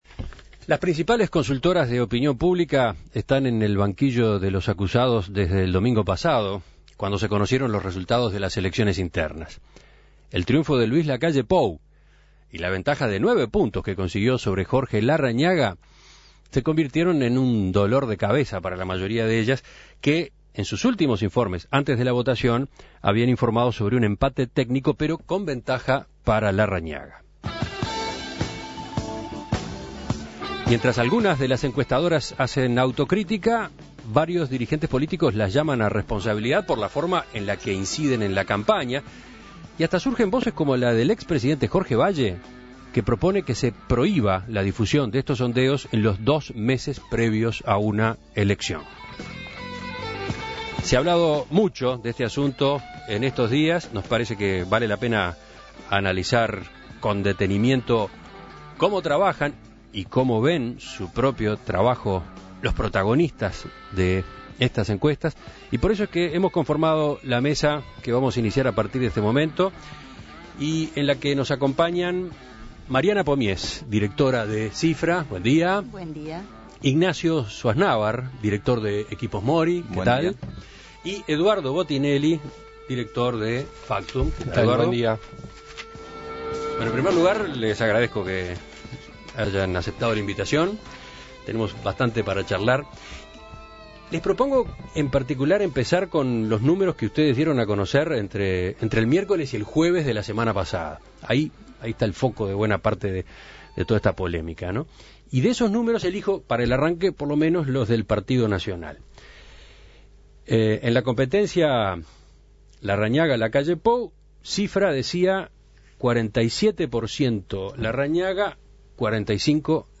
En Perspectiva conversó con los directores de estas empresas para conocer cómo fue su trabajo en los últimos días.